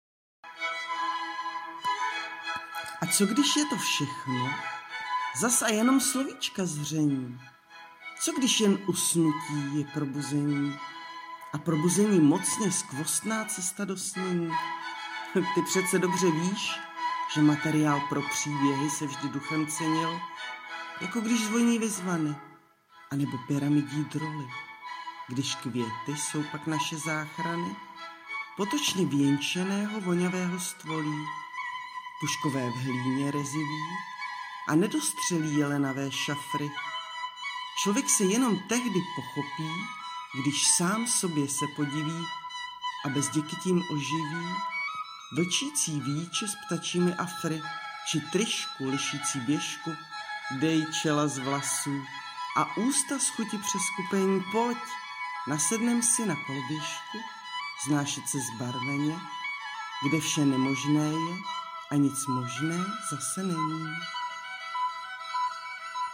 veľmi príjemný hlas krásna báseň